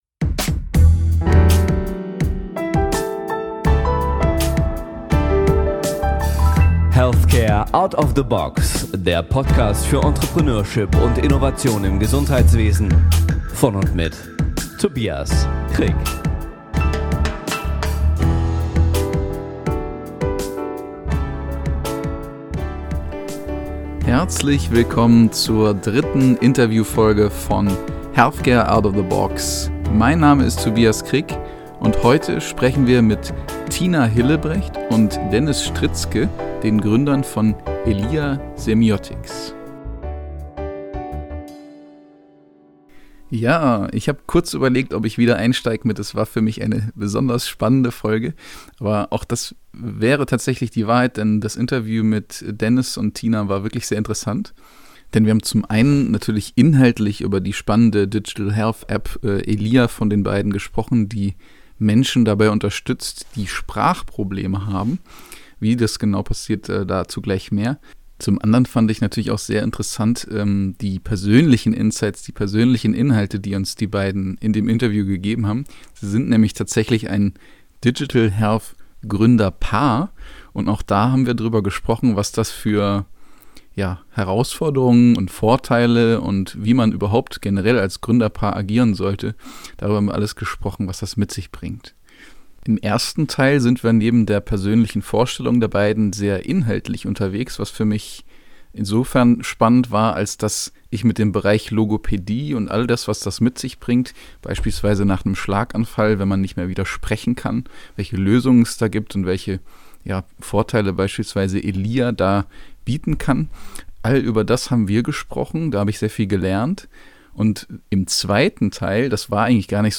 #3 Interview